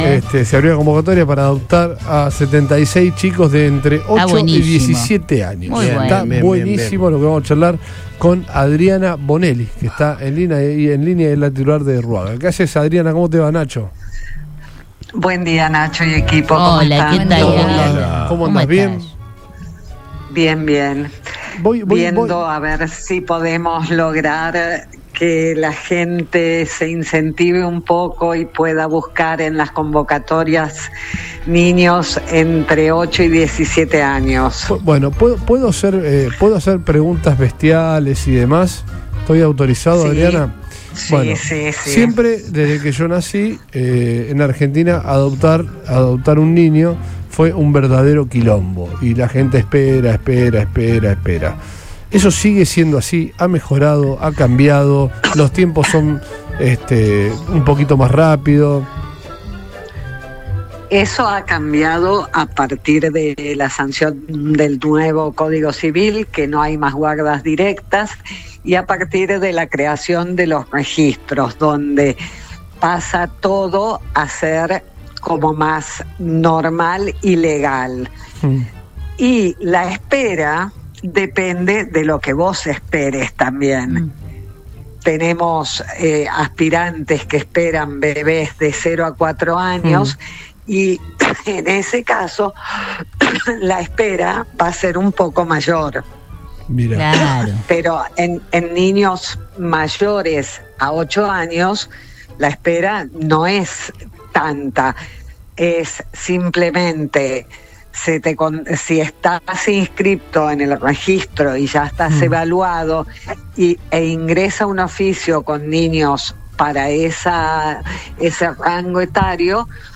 El equipo de Todo Pasa de Radio Boing, dialogó con la directora del Registro Único de Aspirantes a Guarda con Fines Adoptivos (Ruaga) de la provincia de Santa Fe Adriana Bonelli, quien dio detalles de la convocatoria publica informada por la Provincia de Santa Fe, en donde hay un total de 76 niños y niñas, con edades comprendidas entre 8 y 17 años, aguardan la posibilidad de encontrar una familia adoptiva que les brinde amor y estabilidad.